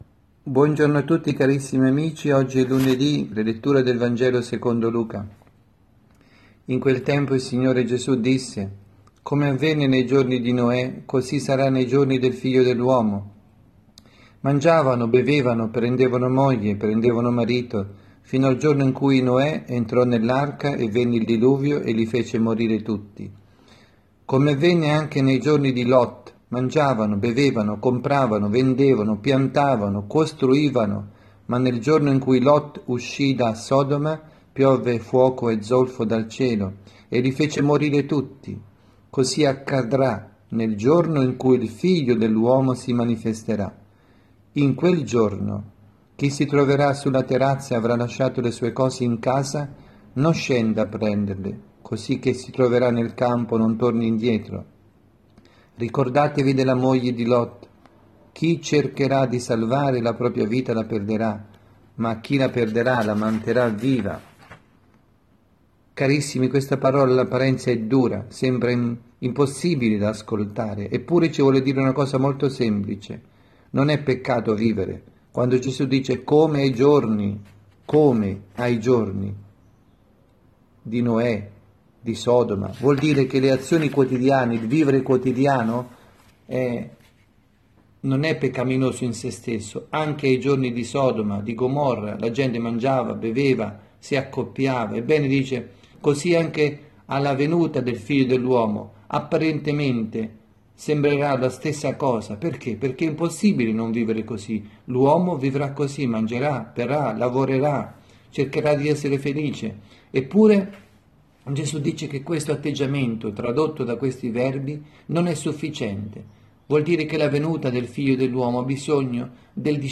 avvisi, Omelie
dalla Parrocchia S. Rita – Milano